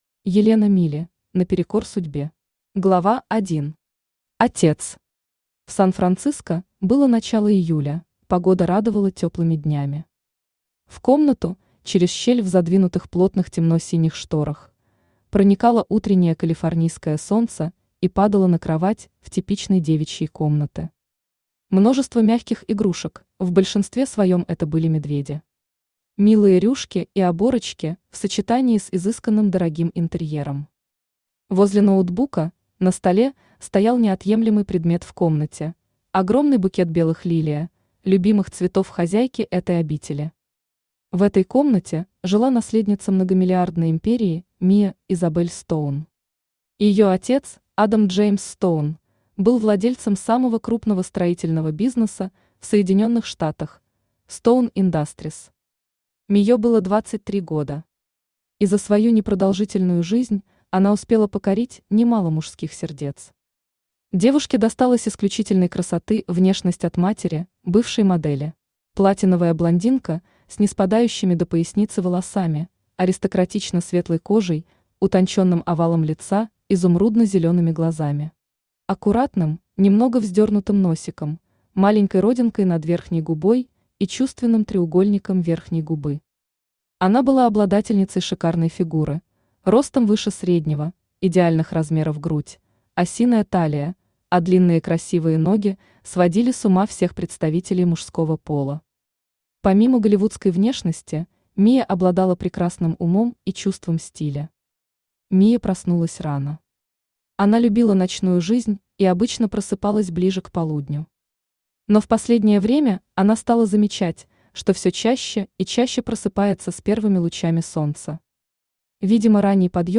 Aудиокнига Наперекор судьбе Автор Елена Милли Читает аудиокнигу Авточтец ЛитРес.